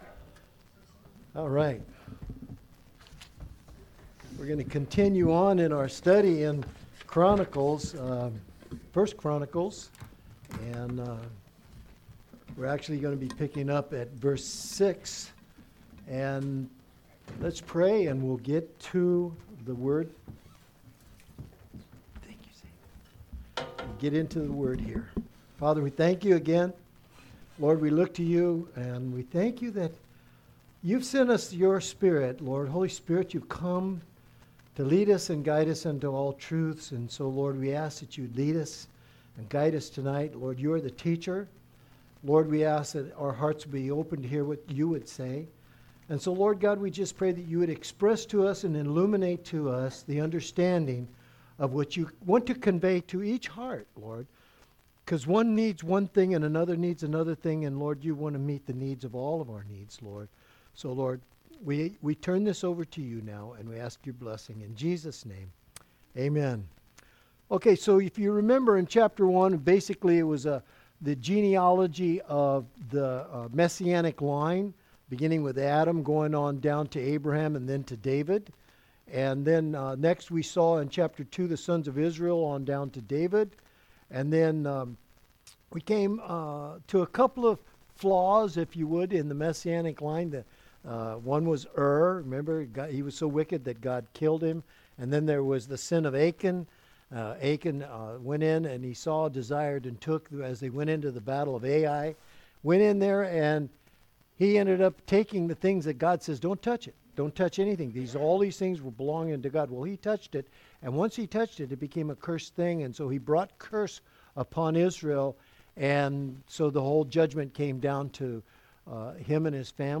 Sermons by Calvary Chapel Lowcountry